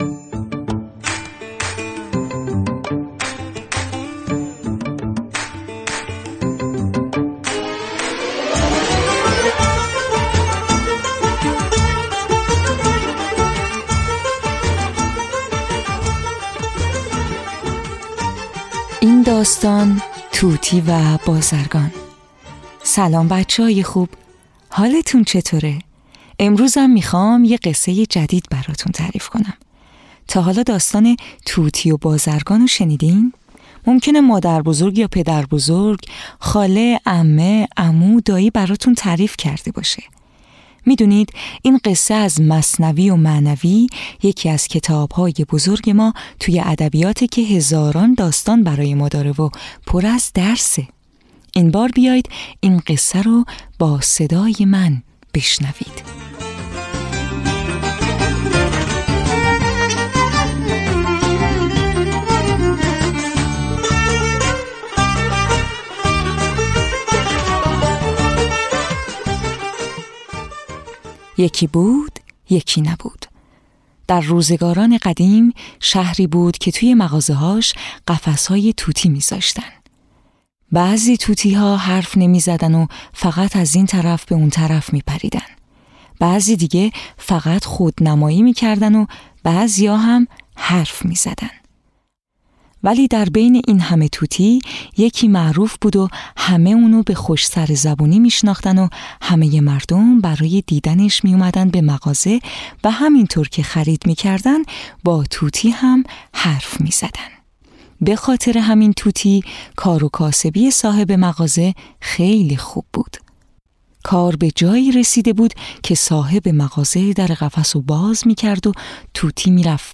قصه های کودکانه صوتی - این داستان: طوطی و بازرگان
تهیه شده در استودیو نت به نت